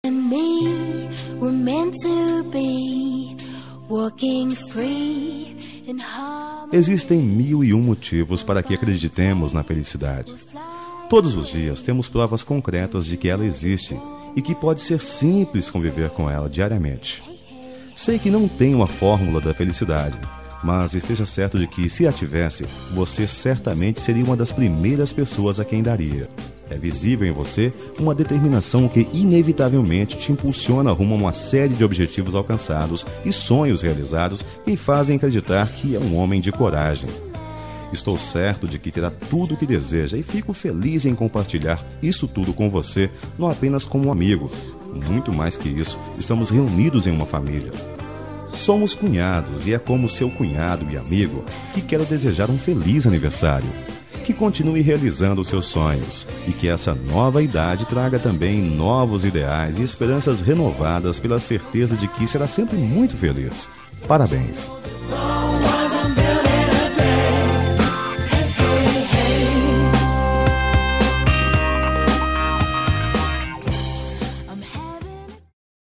Aniversário de Cunhado – Voz Masculina – Cód: 5234